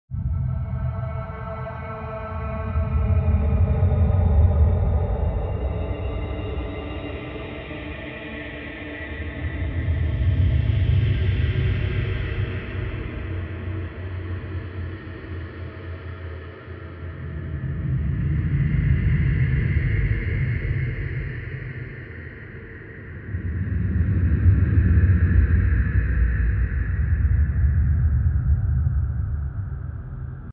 Звуки тревоги
В коллекции — резкие сирены, нарастающие гудки, тревожные оповещения и другие эффекты, усиливающие напряжение.